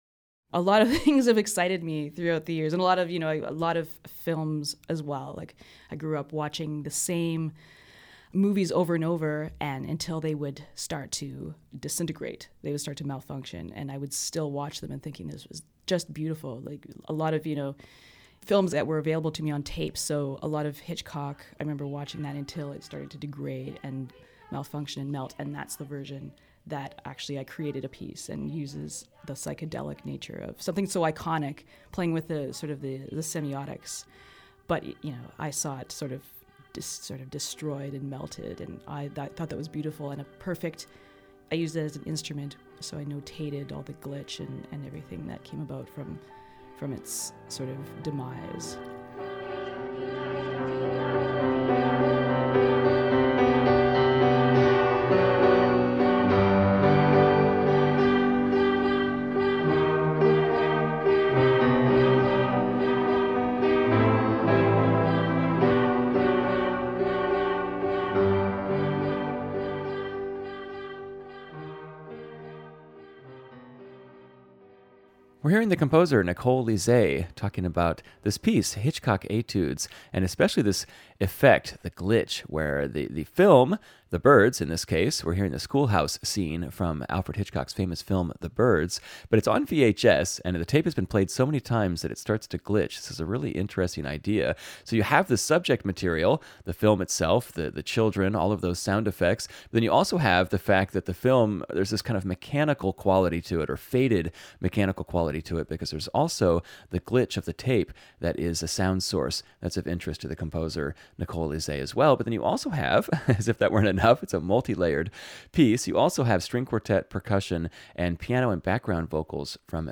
cello
Turntables
turntables & omnichord
piano & background vocals